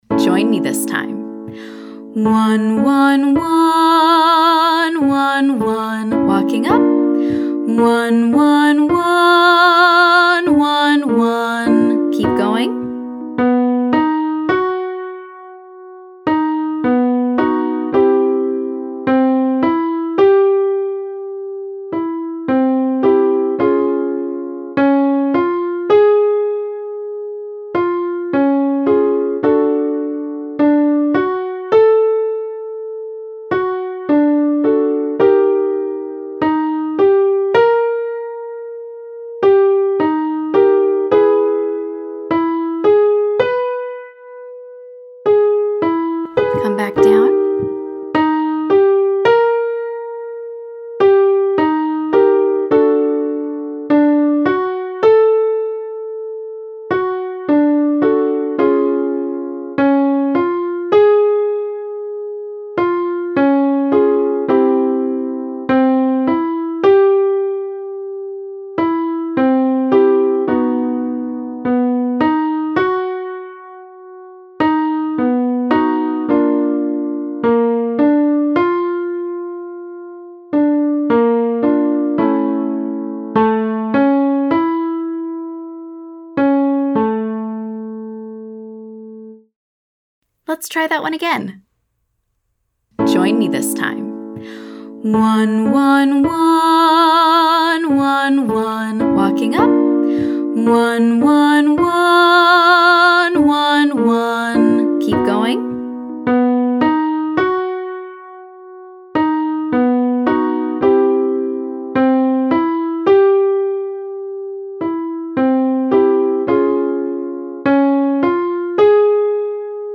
Because most instances of belting include at least some sustain, let’s sustain that top note.
Exercise 2: Sustained ONE/WUH 135—31